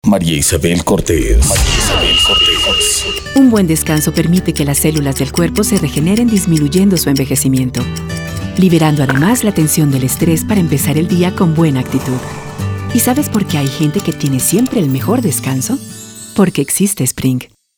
Feminino
Espanhol - América Latina Neutro
comercial